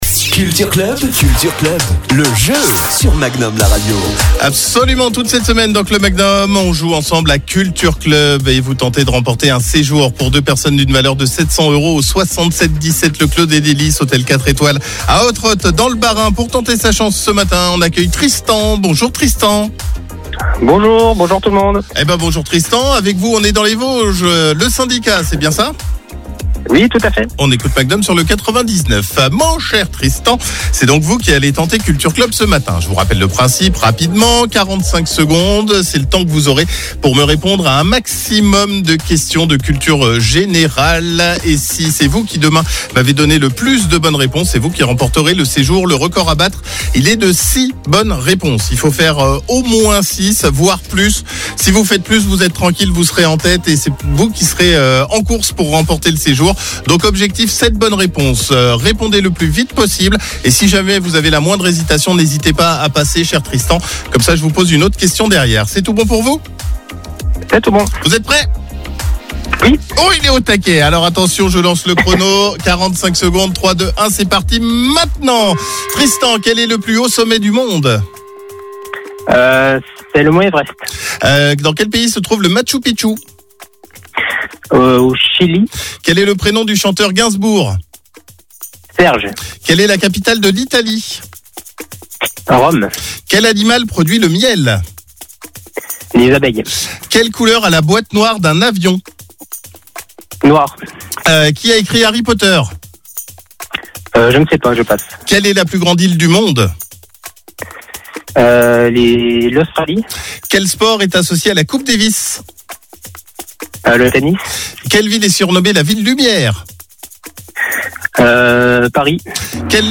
Le candidat devra donner un maximum de bonnes réponses à des questions de culture générale (vie quotidienne, people, cinéma, musique, histoire, géographie etc...).